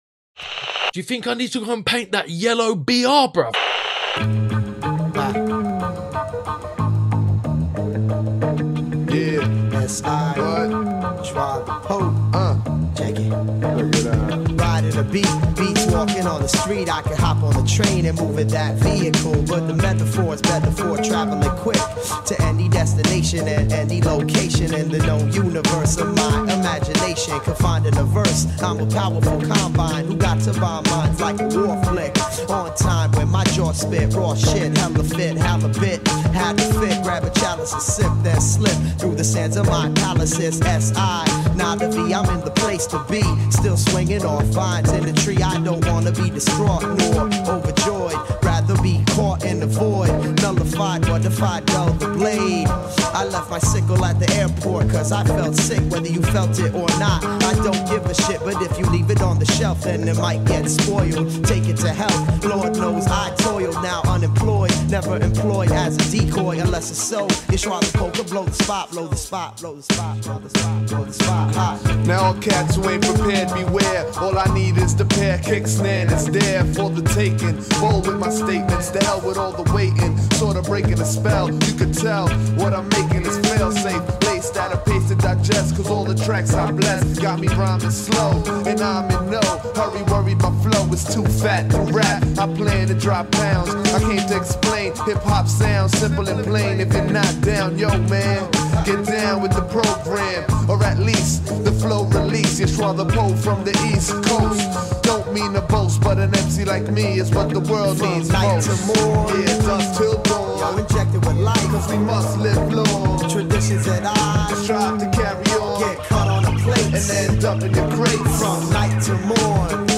Another location episode! Some derelict squat-type-place and we painted the Sports Hall...